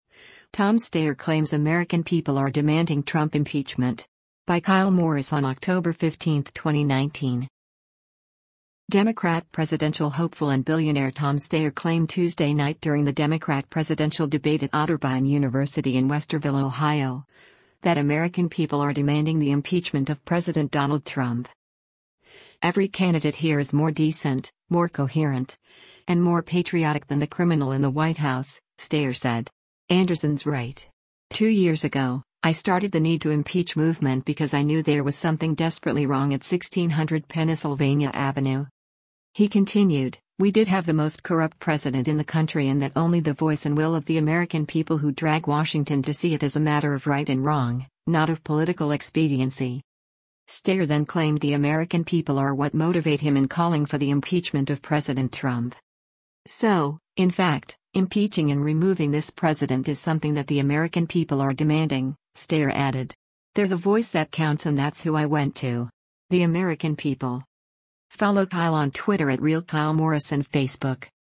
Democrat presidential hopeful and billionaire Tom Steyer claimed Tuesday night during the Democrat presidential debate at Otterbein University in Westerville, Ohio, that “American people are demanding” the impeachment of President Donald Trump.